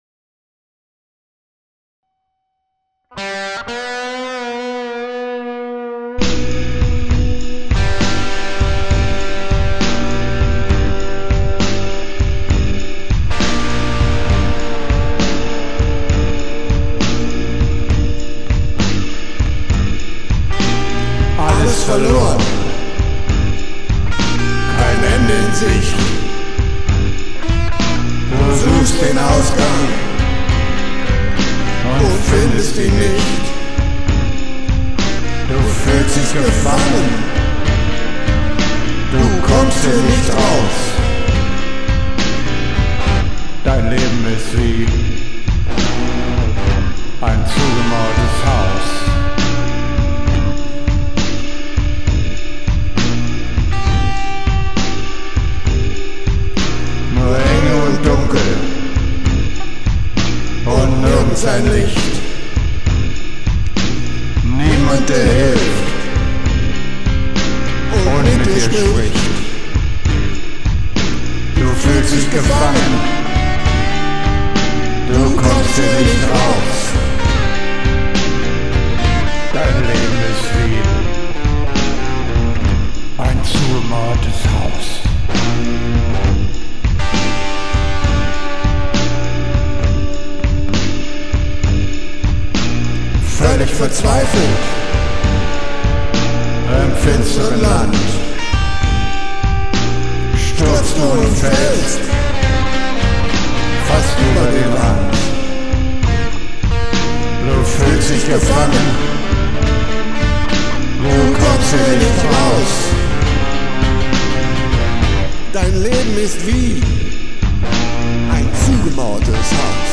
Wieder einen Blues produziert: